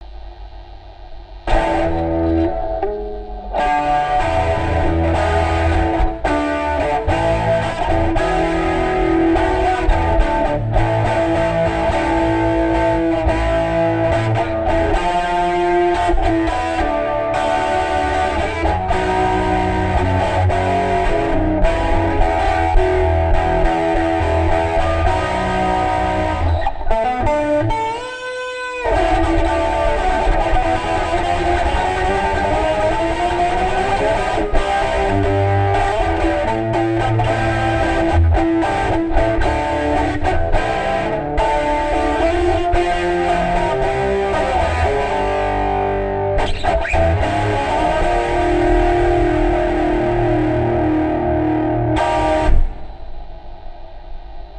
Rough guitar
Above you see the controls of (clock-wise, starting in upper left corner) Jamin, a heavy fft-based multiband compressor/32/1024 band stereo equalizer/analyzer with boost control, jack's connections between the programs shown and the Lexicon AD (with guitar input) and DA converters, jack-rack with a nice plate reverb, stereo compressor, stereo delay and volume control and the guitar input processsing rakarack guitar effect processor suite with eq, compression, overdrive and chorus effects on.
Oh yeah, in spite of the heavy hum from the notebook supply unit (putting on battery makes it quiet), this is the result, judge for yourself:
.wav guitar rough  (CD stereo quality, 9MB)
Is that a rough minute?
guitarroughtest.mp3